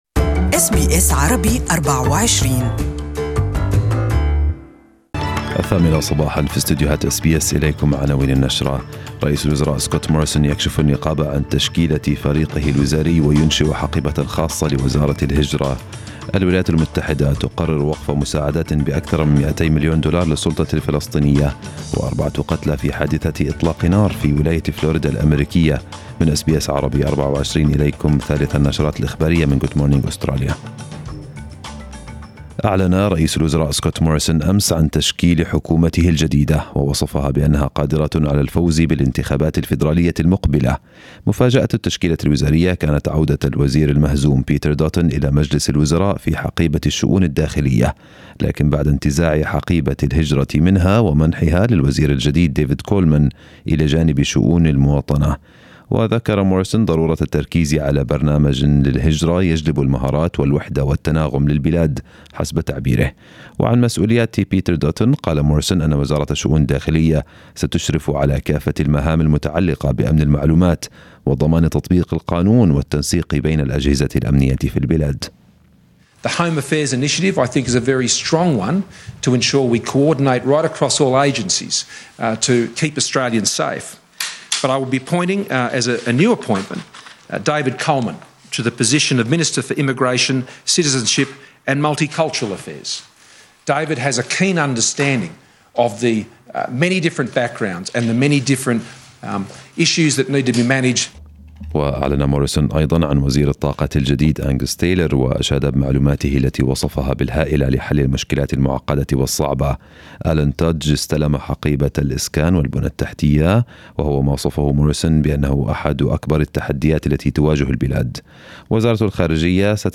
Peter Dutton in, Julie Bishop out and the cabinet swearing-in tomorrow. This and more news in our first news bulletin this morning